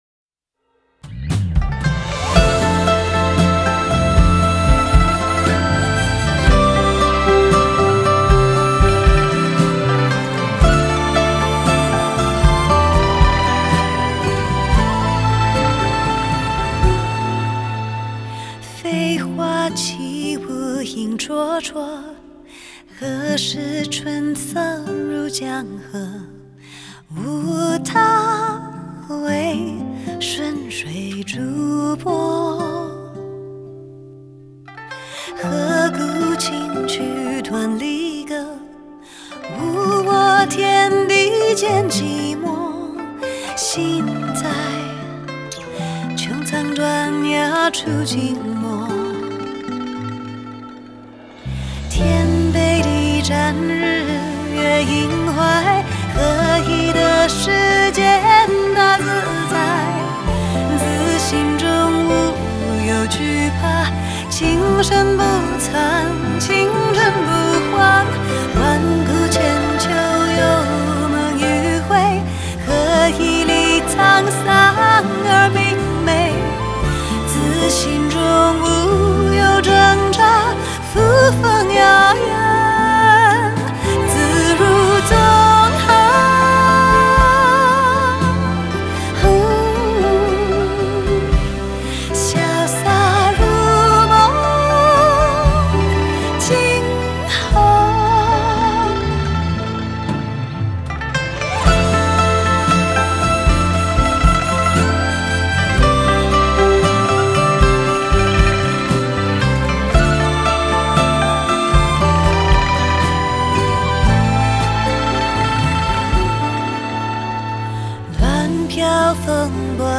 Ps：在线试听为压缩音质节选，体验无损音质请下载完整版
吉他
贝斯
鼓手
弦乐
笛子
琵琶
和音